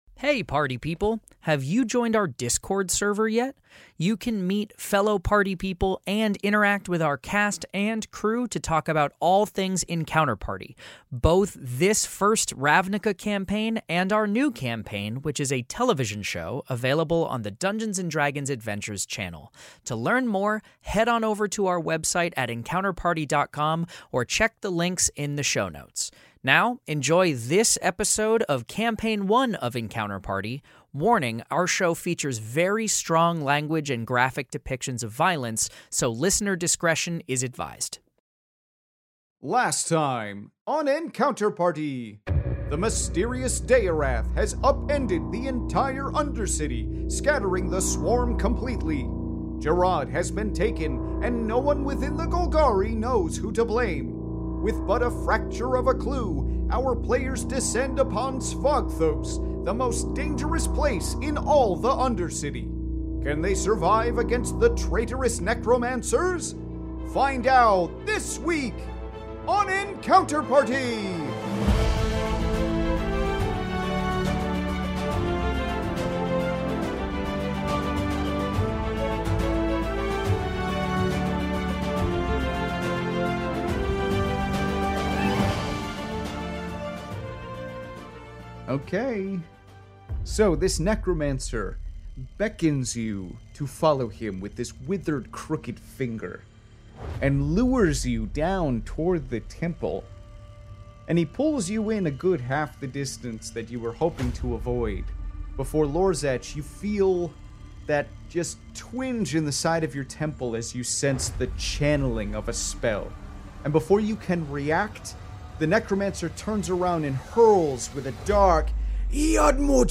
Fantasy Mystery Audio Adventure